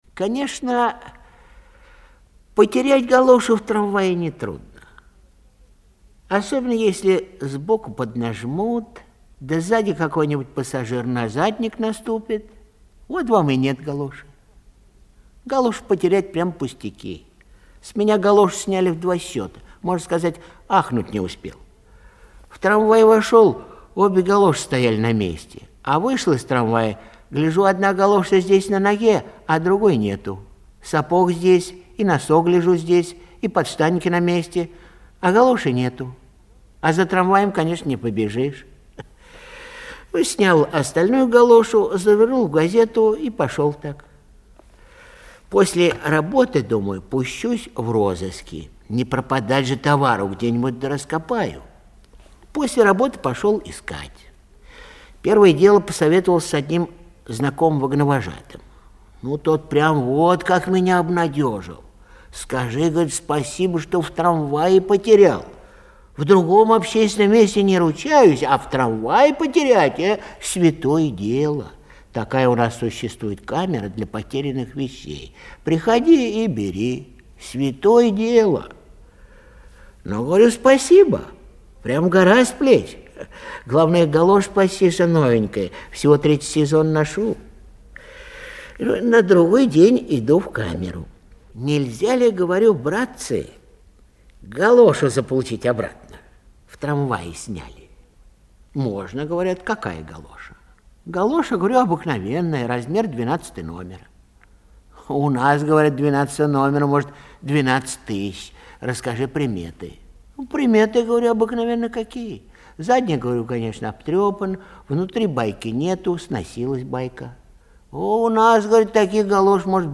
Аудиорассказ «Галоша»
zoshhenko-m.m.-chitaet-ilinskij-i.-v.-galosha.mp3